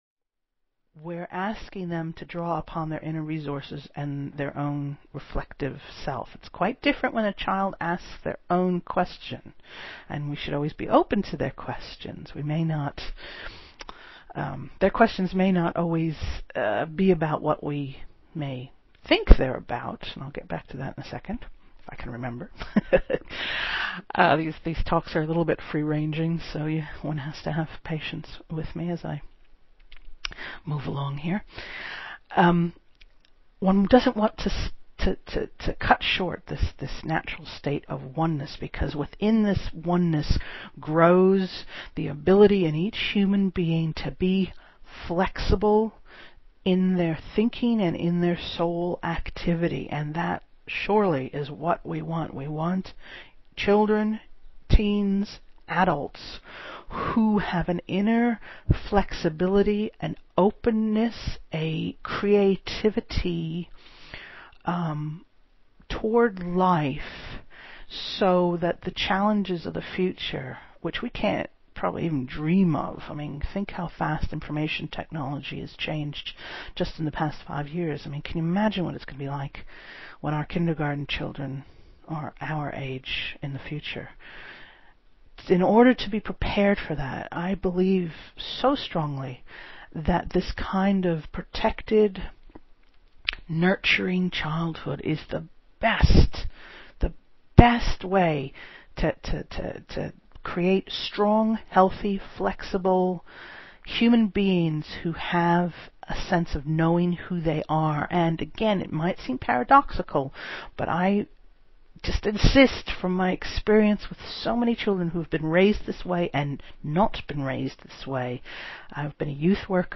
You have a lovely voice, by the way.
I listened to your kindergarten talk this morning, twice, and now I want my husband to listen to it.